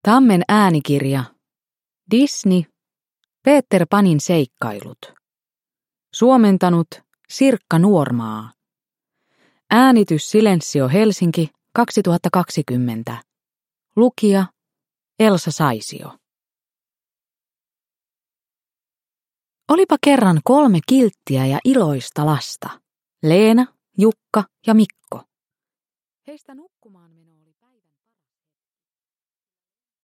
Peter Panin seikkailut. – Ljudbok – Laddas ner